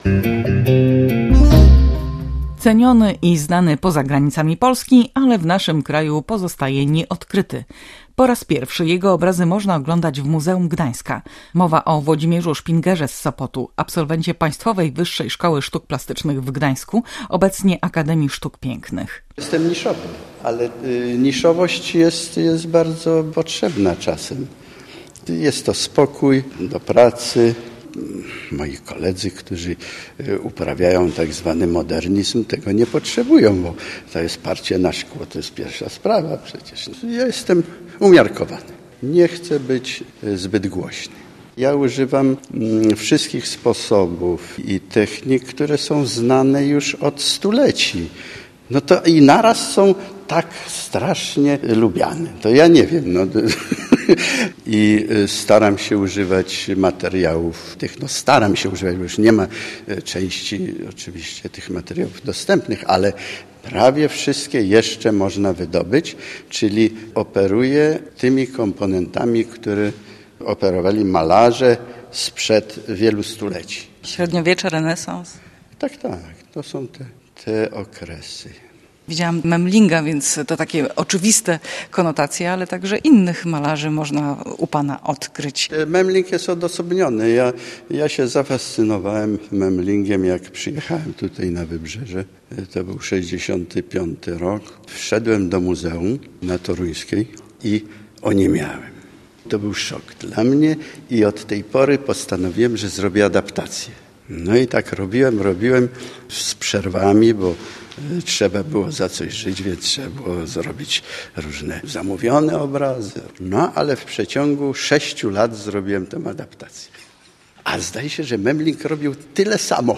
Posłuchaj fragmentu audycji Tygiel kulturalny